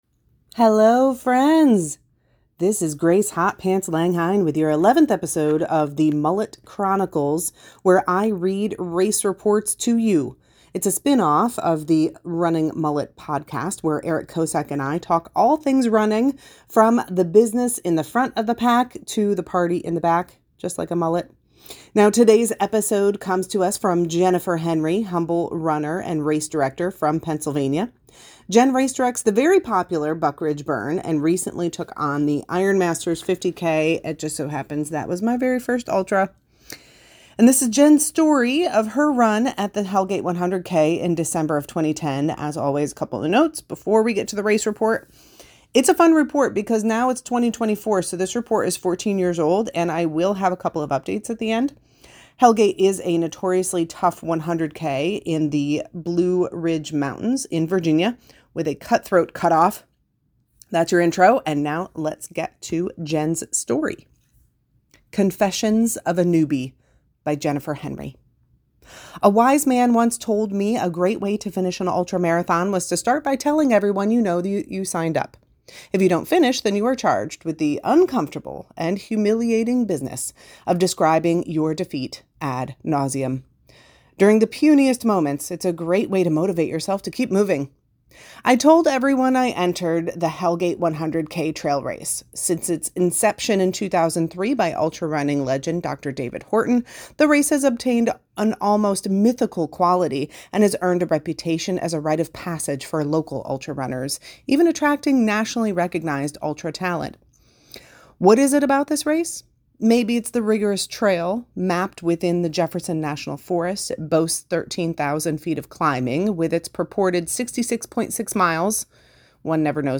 I read race reports! Word for word.